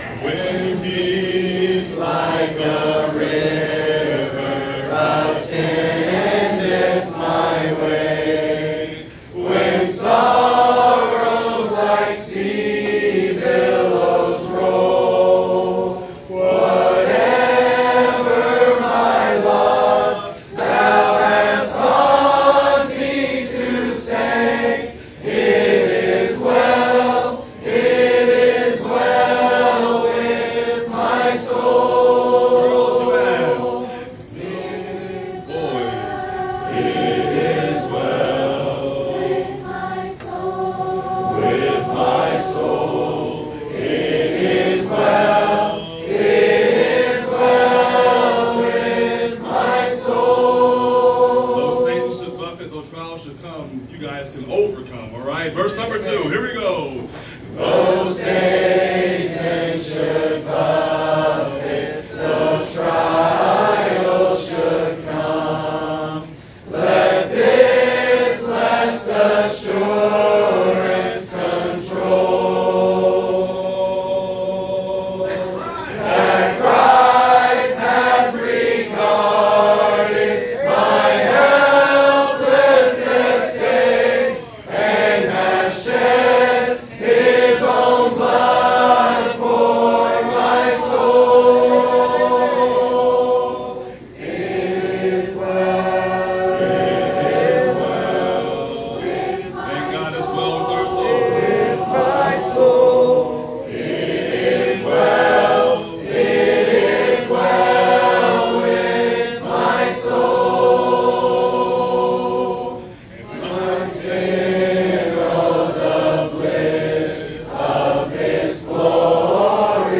Camp Songs